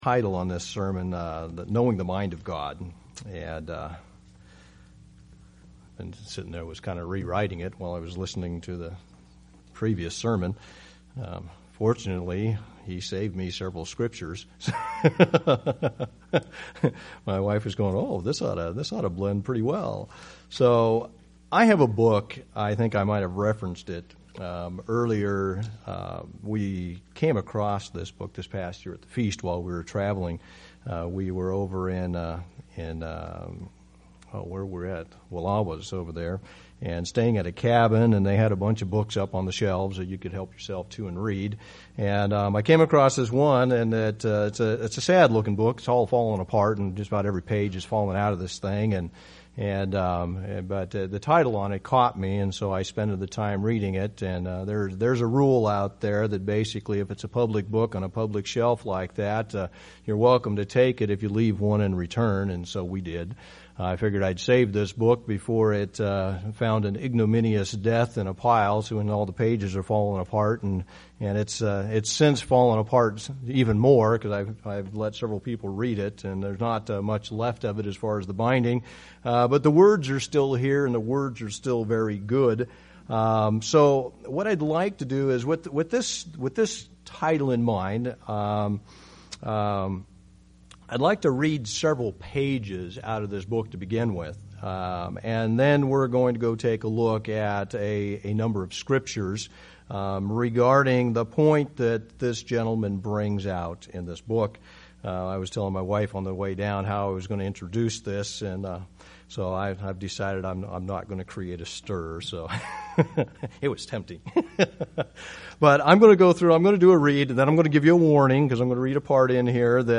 Given in Medford, OR
UCG Sermon Studying the bible?